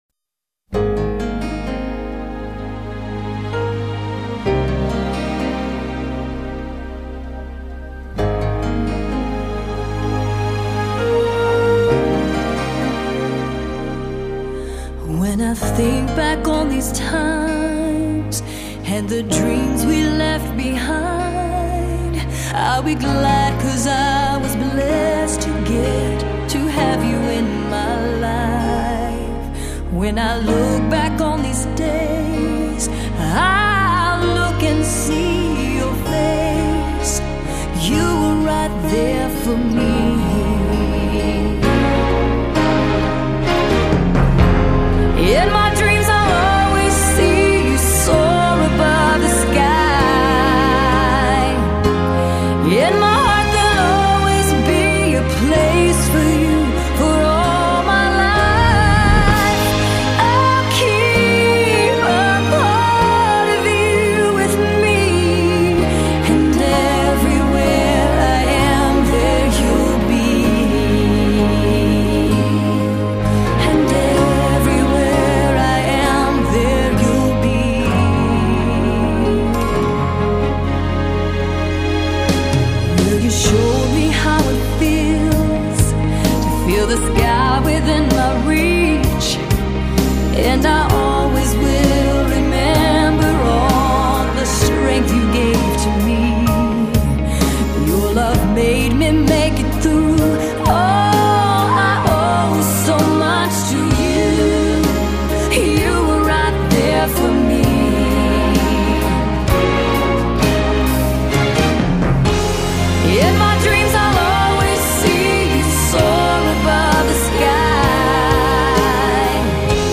音乐风格:/电影配乐/POp/流行